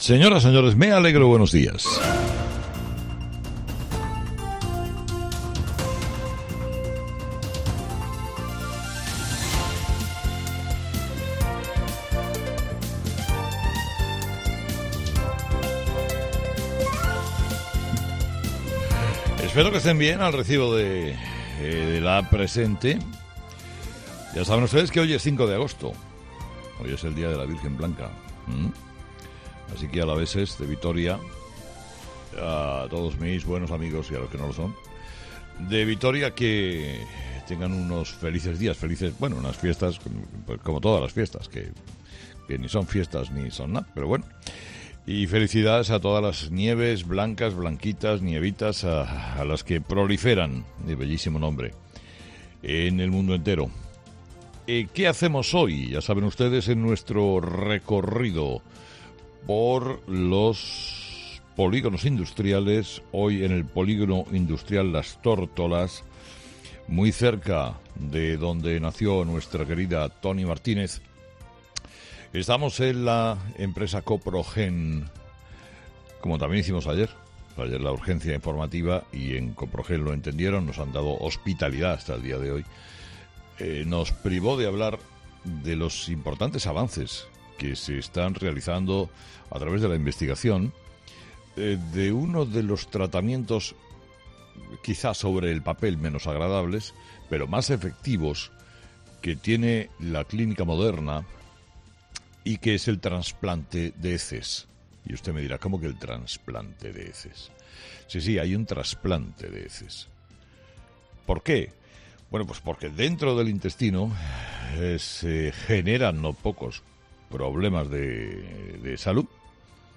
En su monólogo de este miércoles, Carlos Herrera también se ha hecho eco de esta incongruencia.